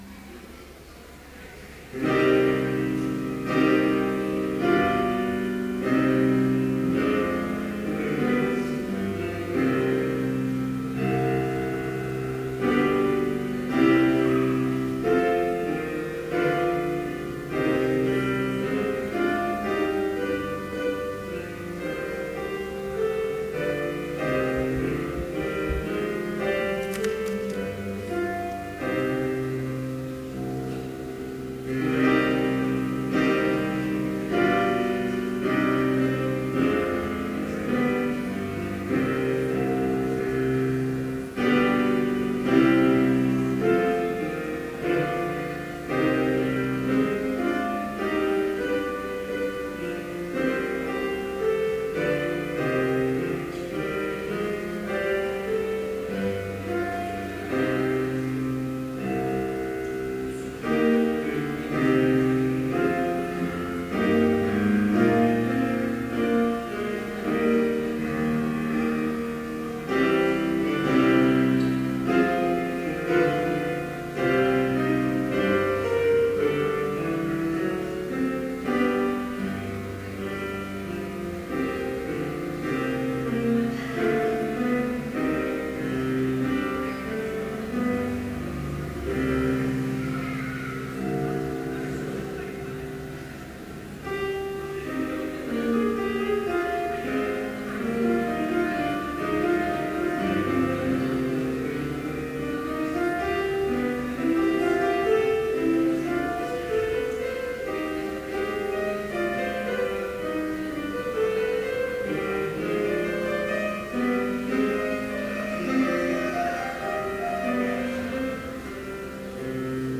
Complete service audio for Chapel - April 4, 2014